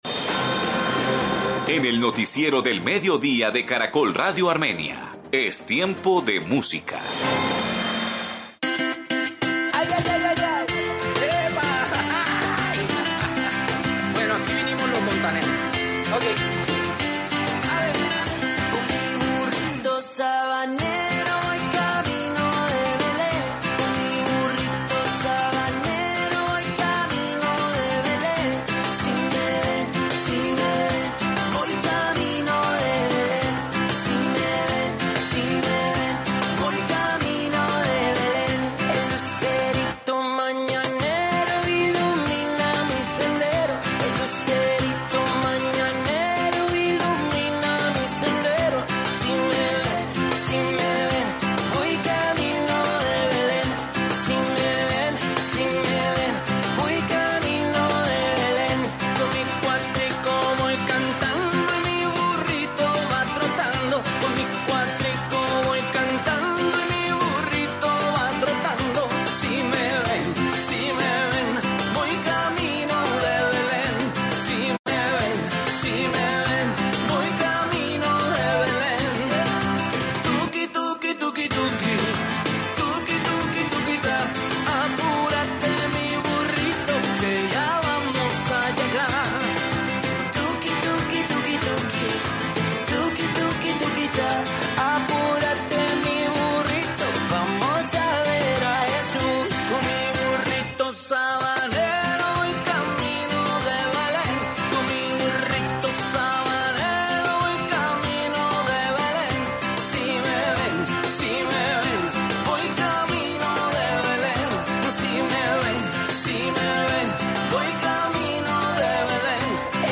Informe concursos de villancicos en Circasia, Quindío